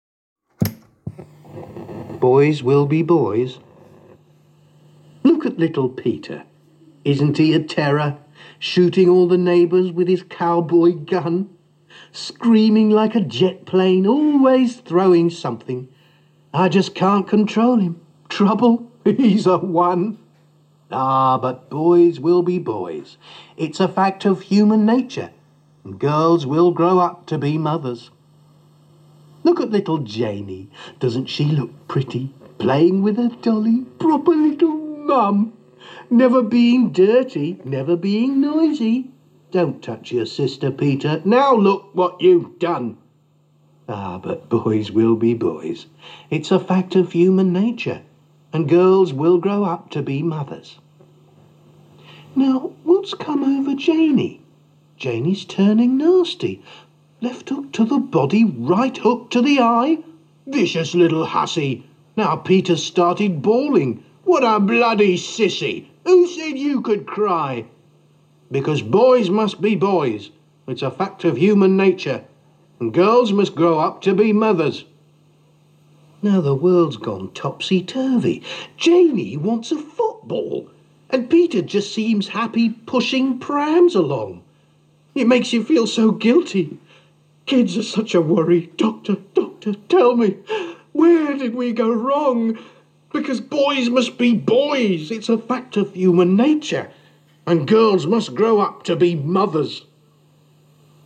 Boyswillbeboys_poem.mp3